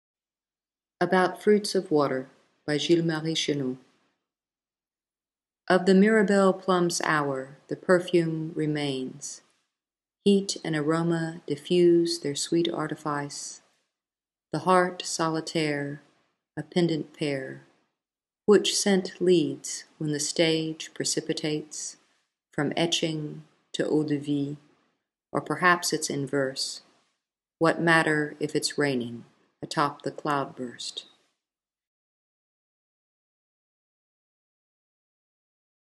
An audio version of the English translation is available below: